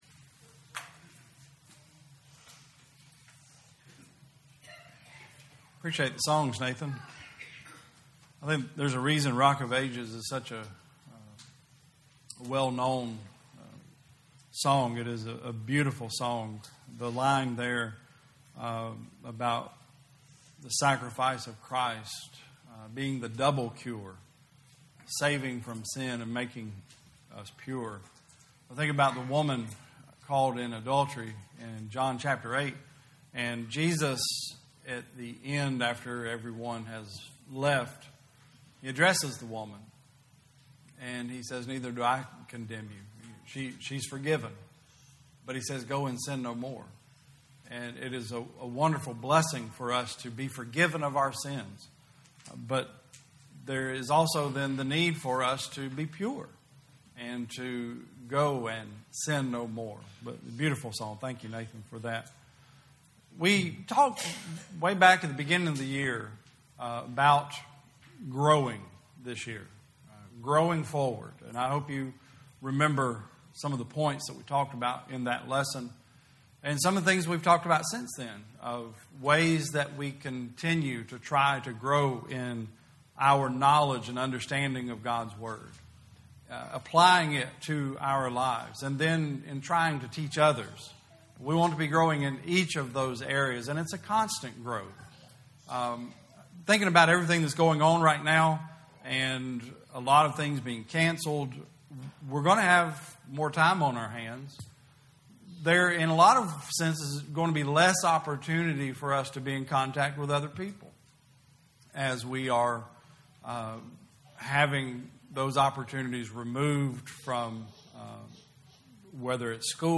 2020 Service Type: Sunday Service Preacher